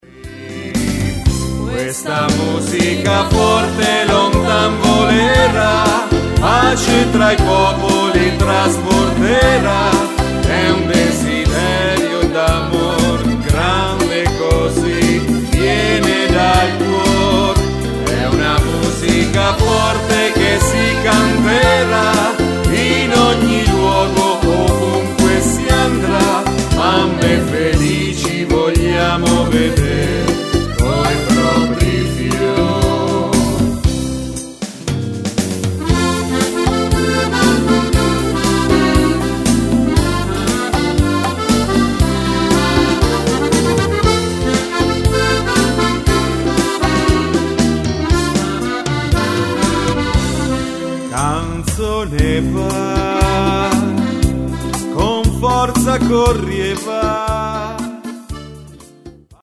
Beguine
Fisarmonica